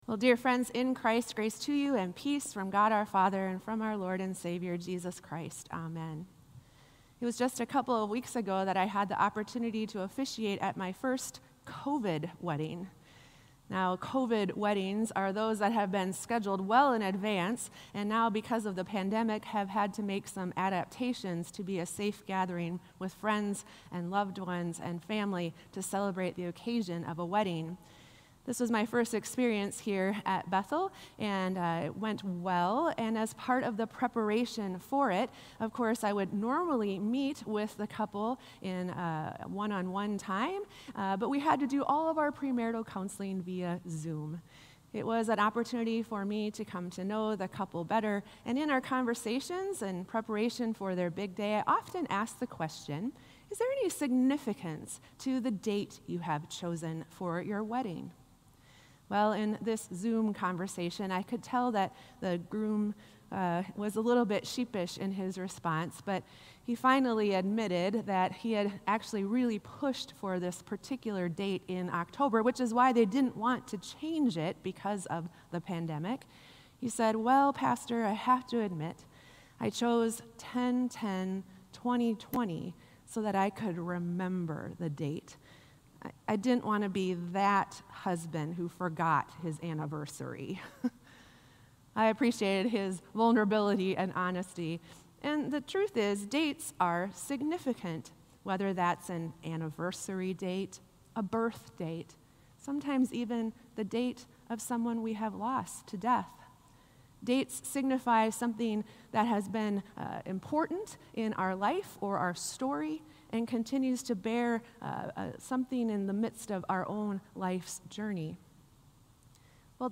Sermon “A Word of Grace”